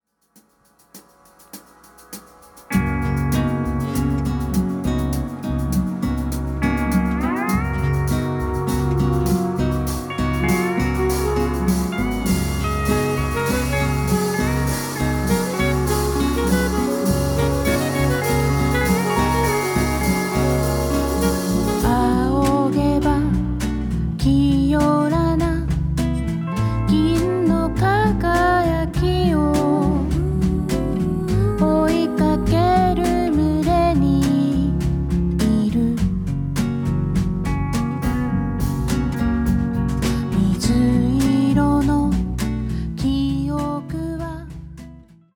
震える子猫を抱くような歌声。
スティール・ギターやサックス、リコーダーにヴィブラフォンも加わった７人体制のバンドサウンドはほんのりポップな装い。